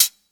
Game_Hat_3.wav